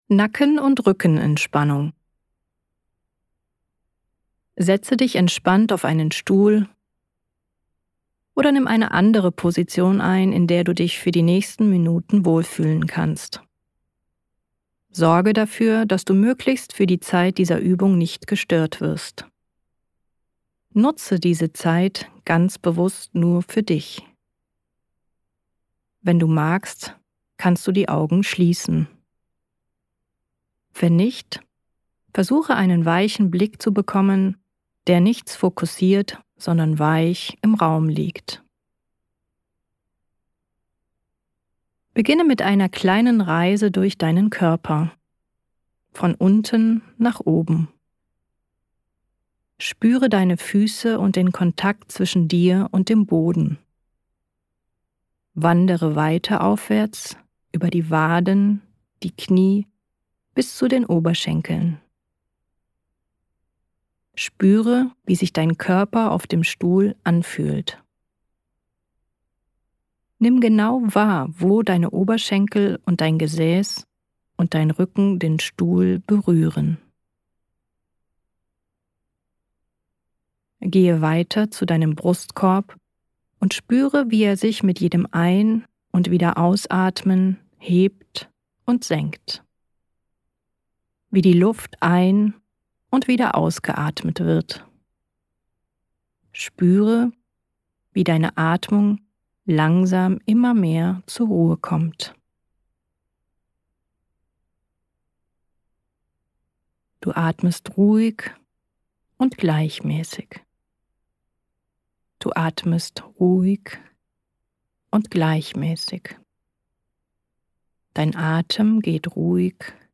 2) Autogenes Training
Hast du Lust ein autogenes Training für Nacken- und Rückenentspannung auszuprobieren?